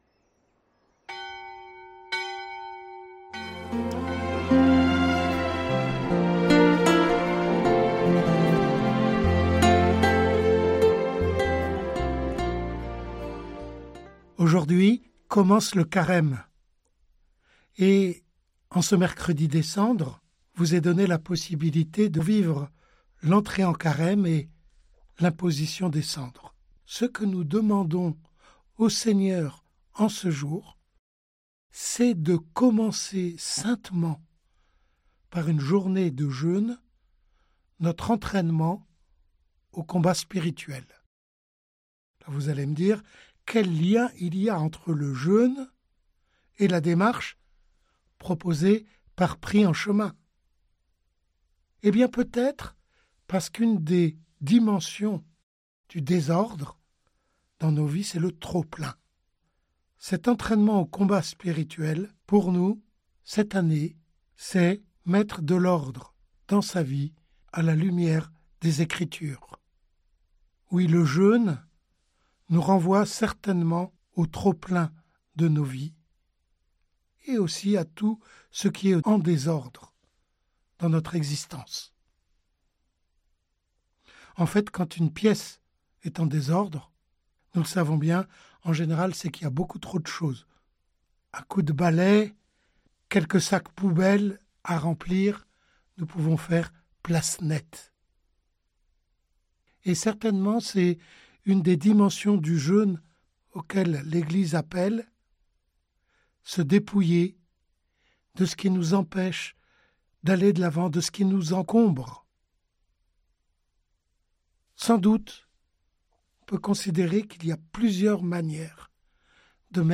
Méditation guidée suivant un livre biblique, une encyclique, des psaumes, ou un thème de la vie chrétienne.